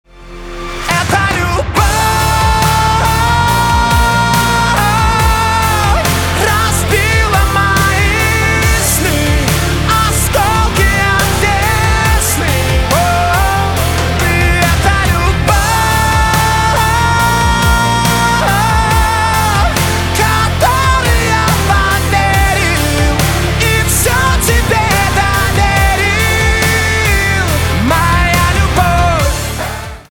поп
чувственные
грустные , гитара , барабаны